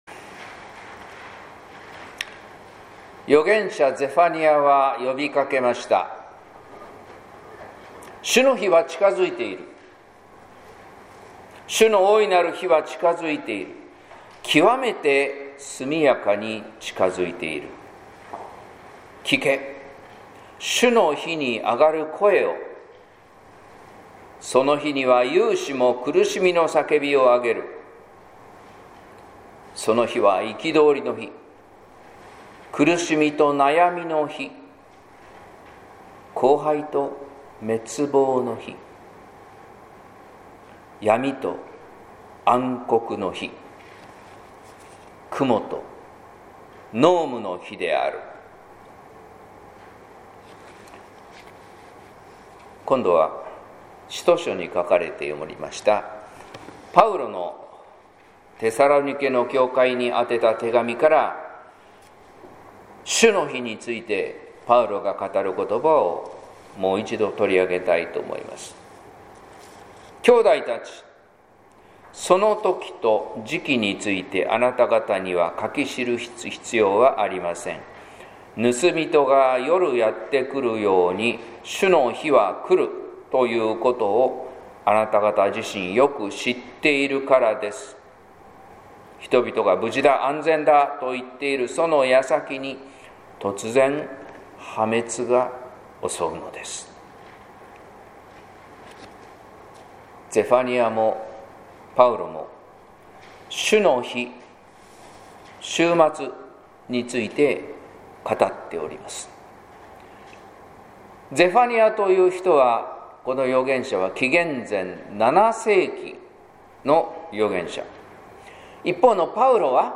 説教「信じるタラントン」（音声版）
聖霊降臨後第25主日